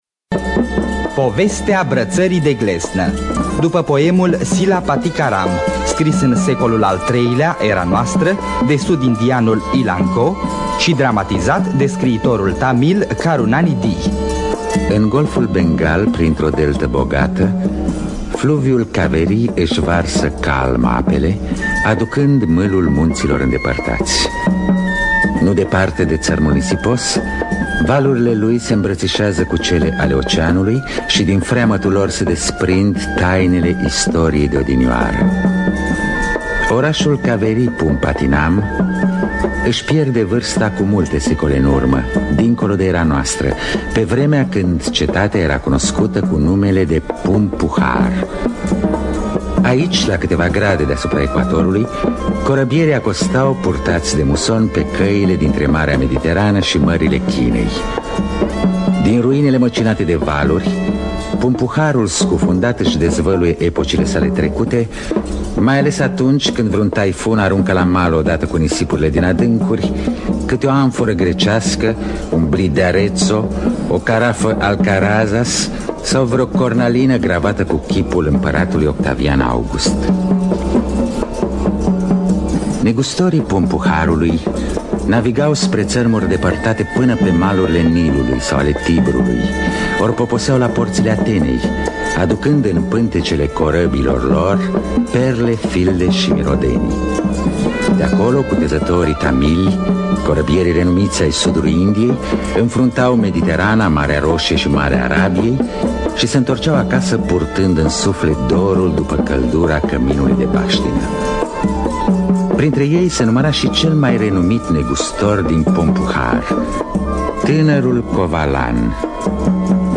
Adaptarea radiofonică
Înregistrare din anul 1969